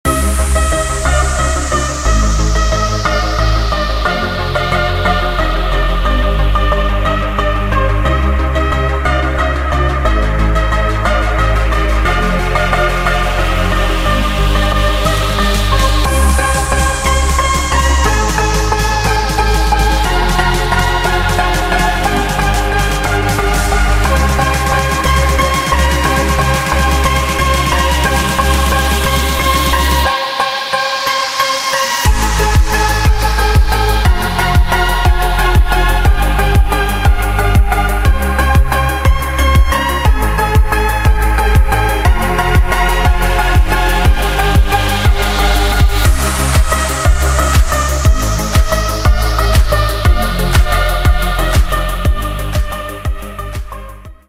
• Качество: 192, Stereo
громкие
deep house
мелодичные
dance
Electronic
deep progressive
Стиль: deep house.